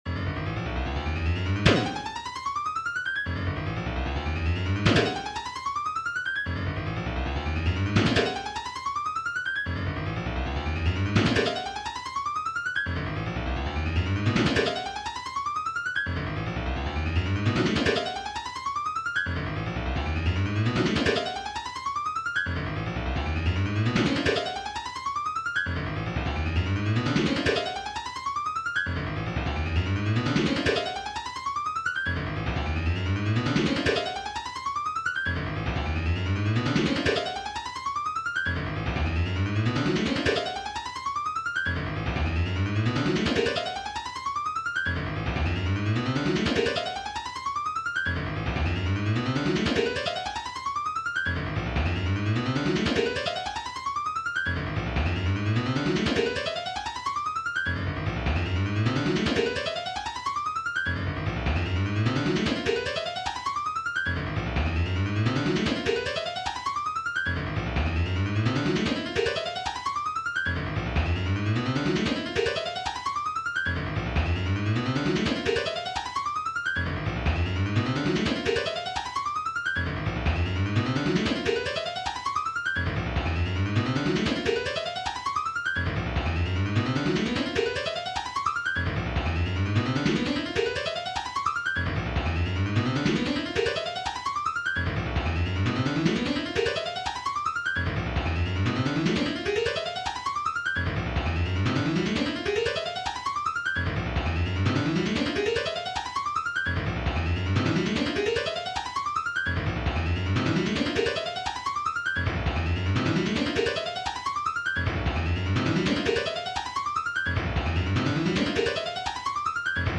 Sonification 1: Sequential tones for each board position
Idea: Assign each board position a MIDI tone (from 1-64), and then play a note of duration 0.1 if the location is occupied and no note (or rest) if the position is unoccupied. To my mind this sounds like a kind of demented Philip Glass: